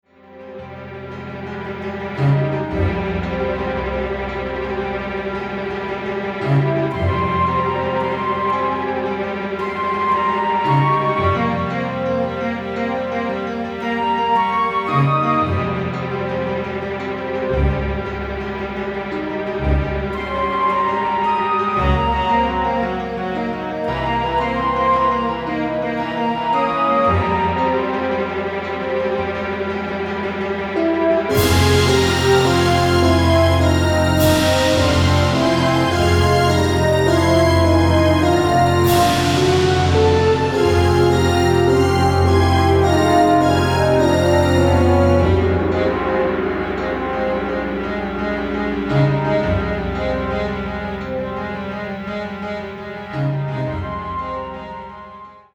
Musical Theater:
keys, orchestration